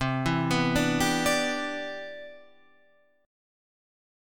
Listen to CM9 strummed